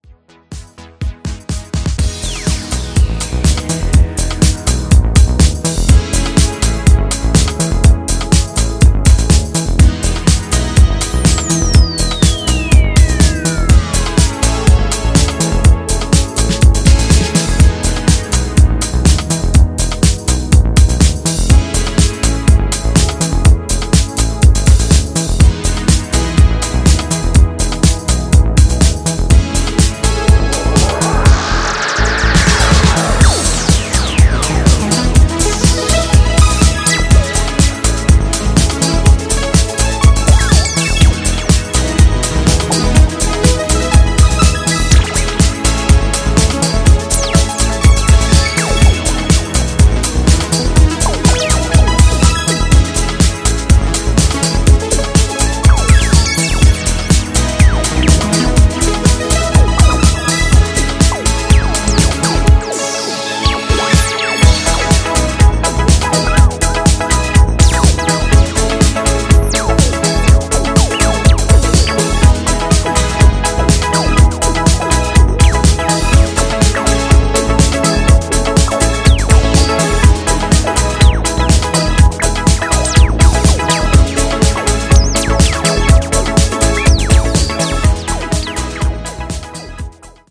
ジャンル(スタイル) NU DISCO / BALEARICA / DEEP HOUSE